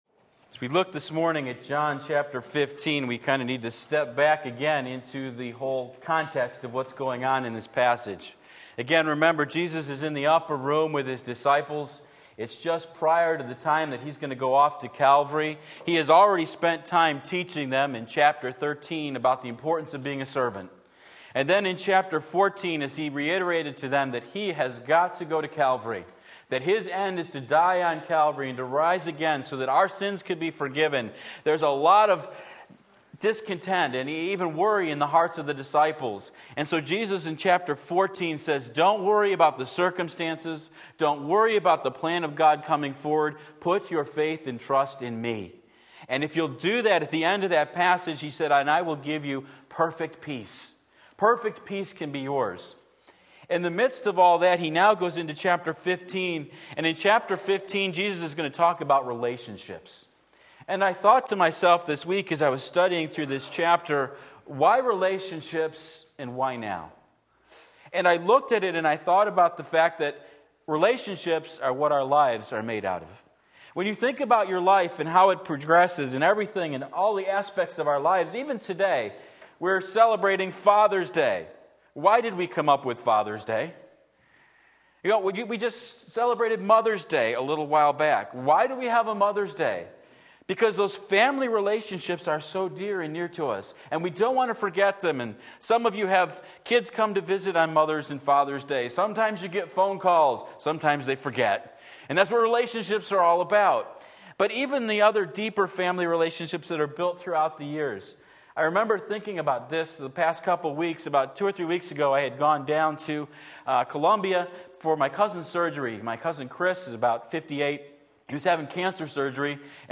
Sermon Link
Abiding in the Vine John 15:1-11 Sunday Morning Service, June 18, 2017 Believe and Live!